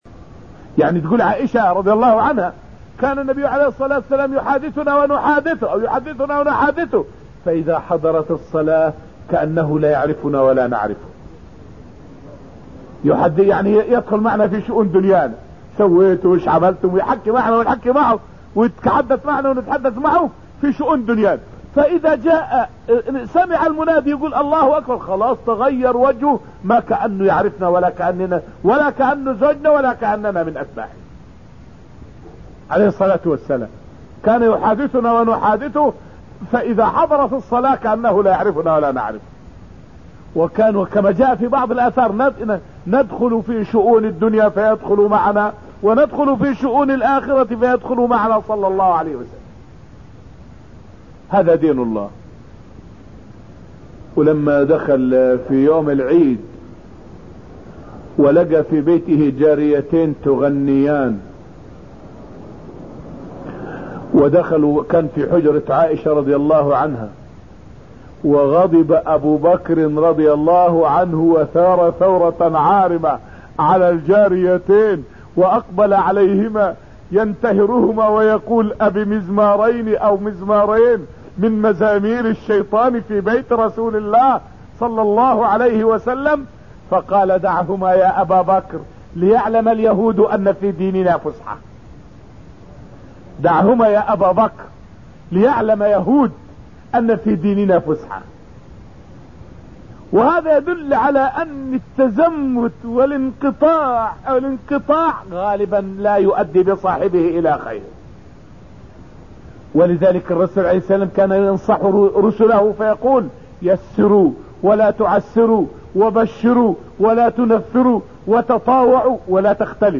فائدة من الدرس الثالث من دروس تفسير سورة المجادلة والتي ألقيت في المسجد النبوي الشريف حول الفرق بين من رمي عائشة وغيرها من المسلمات.